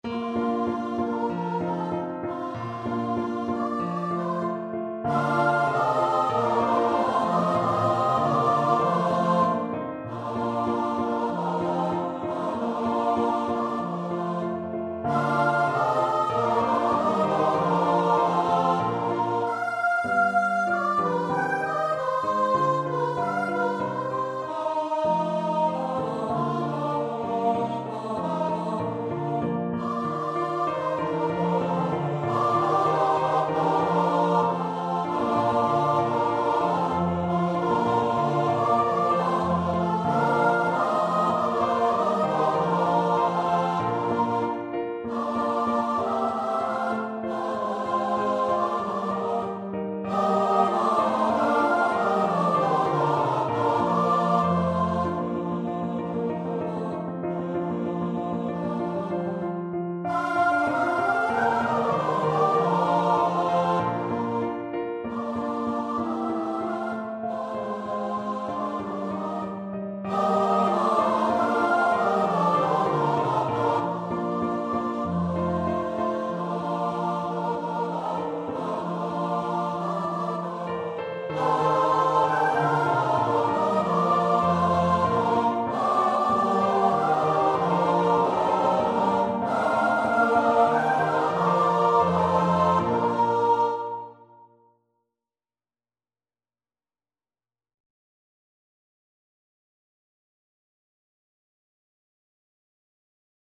Free Sheet music for Choir (SATB)
Choir  (View more Intermediate Choir Music)
Classical (View more Classical Choir Music)